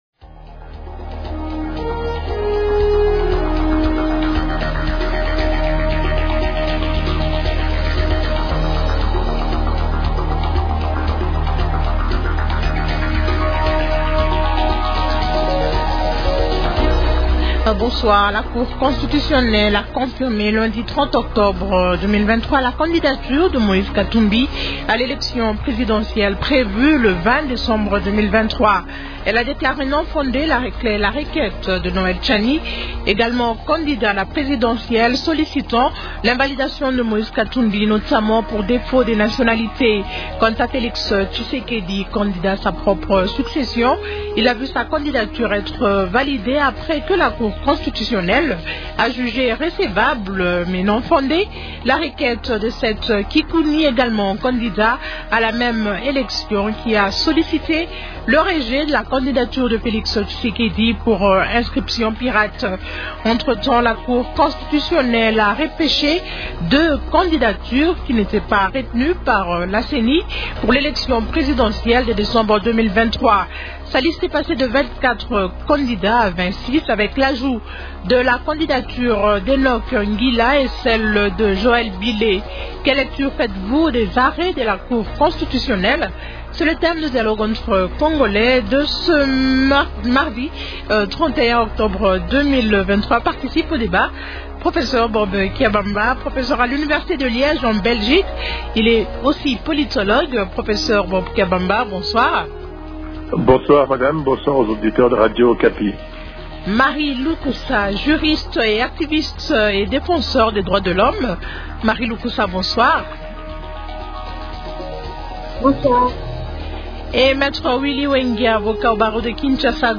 L'actualité politique de ce soir